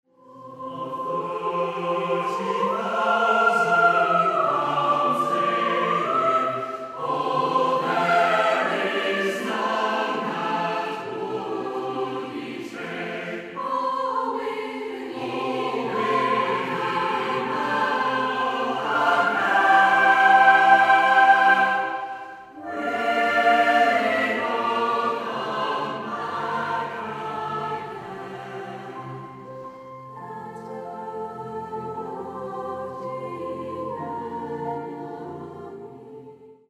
SATB (4 voices mixed).
Folk music.
Instruments: Piano (1)
Tonality: F major